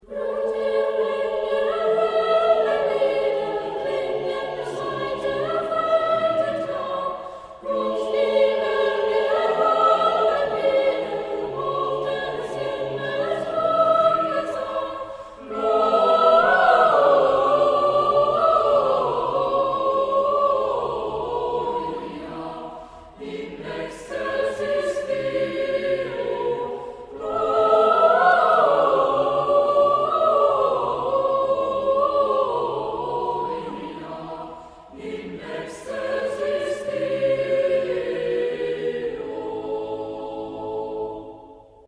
Chorproben MIDI-Files 469 midi files